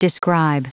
Prononciation du mot describe en anglais (fichier audio)
describe.wav